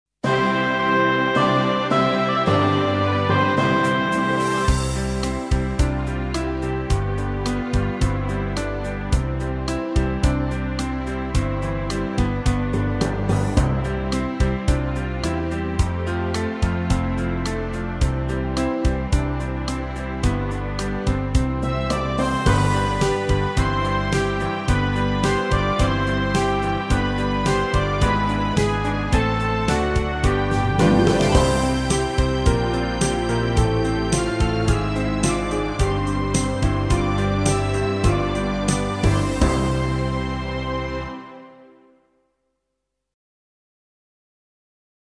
Meespeel CD